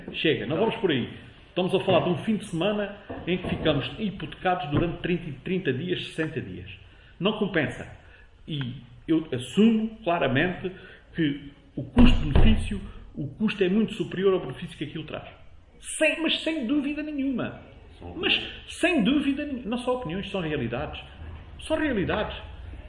O Raid foi tema em discussão na última reunião de Câmara Municipal de Gavião.
ÁUDIO | PRESIDENTE DA CÂMARA MUNICIPAL, JOSÉ PIO: